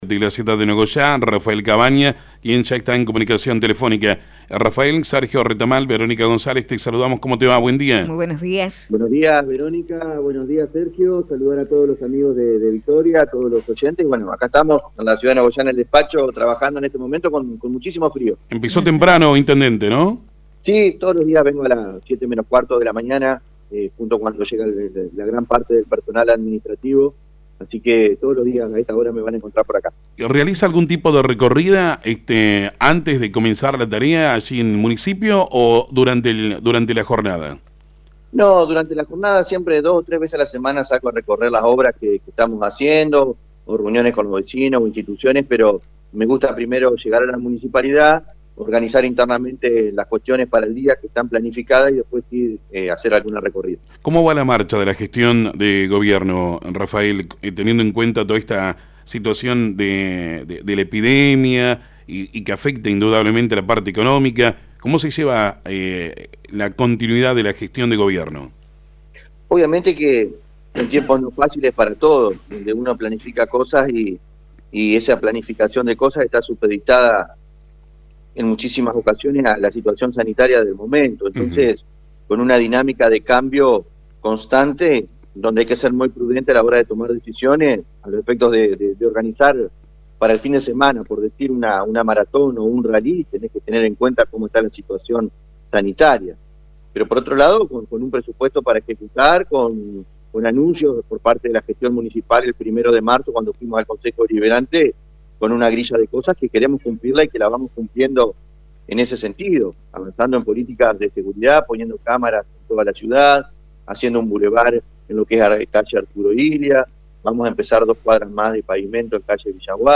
El Intendente Rafael Cavagna habló en FM 90.3 sobre la situación epidemiológica en Nogoyá – Lt39 Noticias
Entrevistas